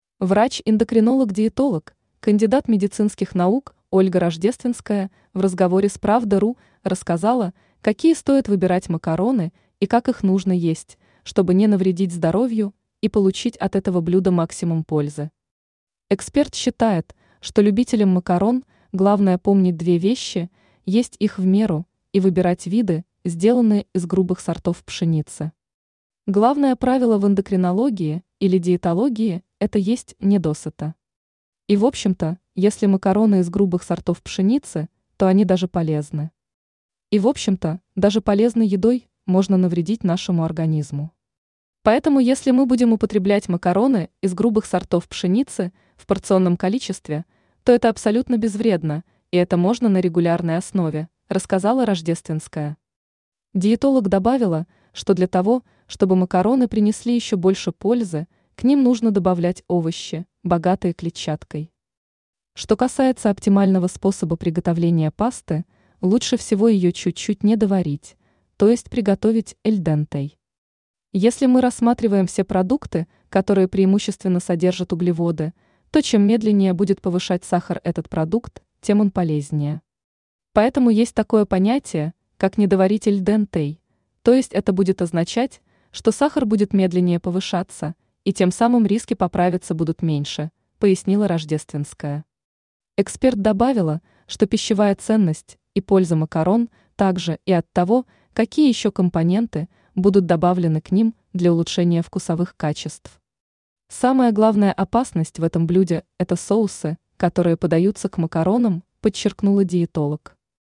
скачать интервью в txt формате